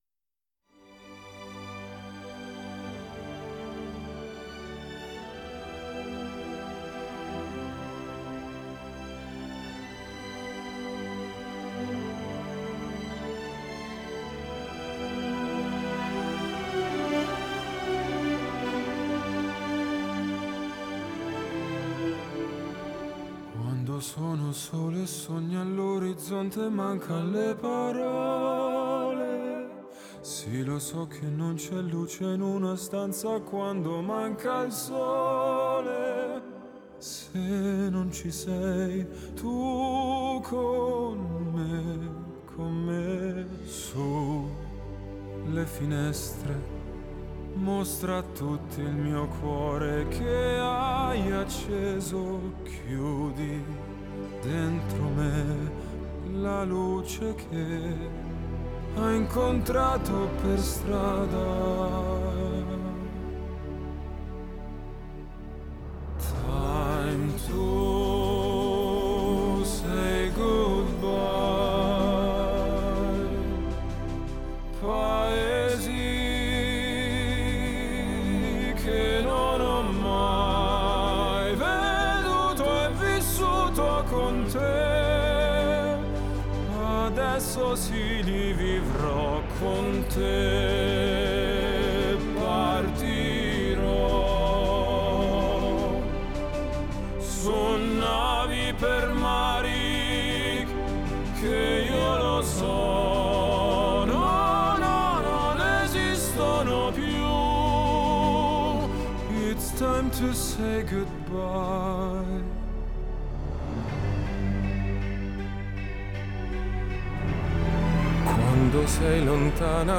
Classical / Operatic Pop / Vocal Crossover